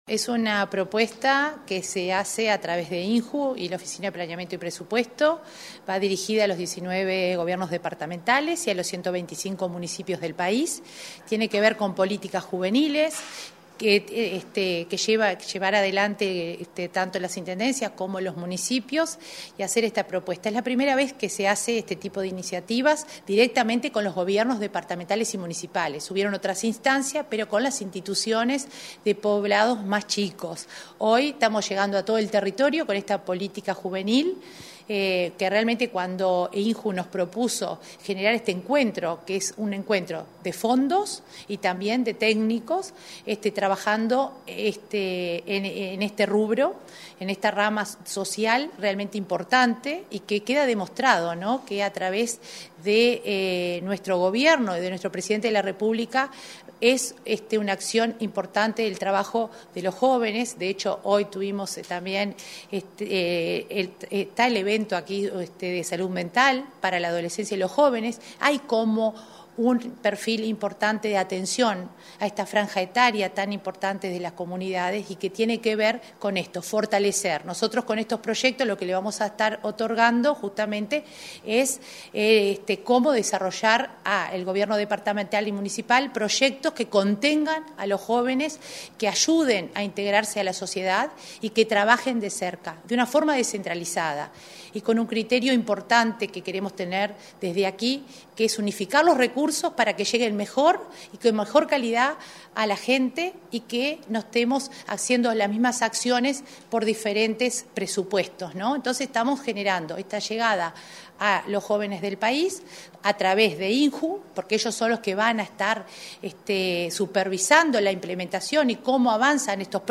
Entrevista a la coordinadora de Descentralización y Cohesión de OPP, María de Lima